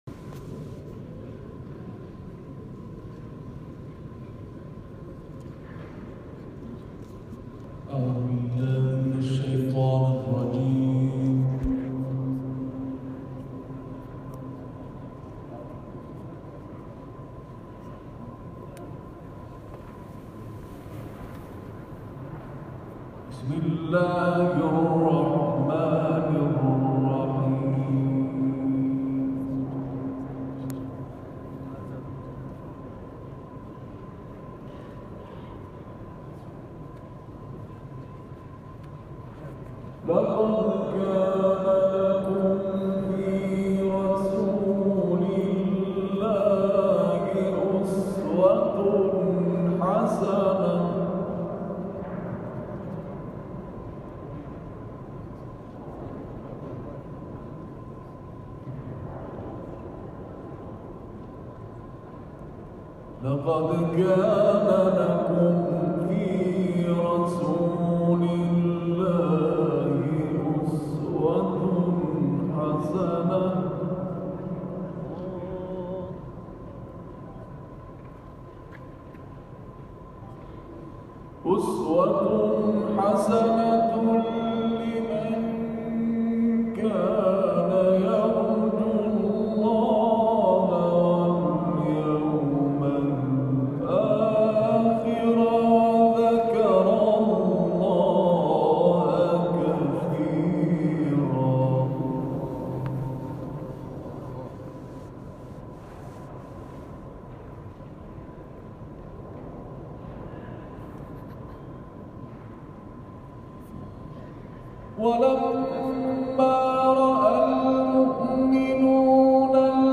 صوت/ تلاوت‌های کاروان انقلاب در آستان امامزاده سیدعلی(ع)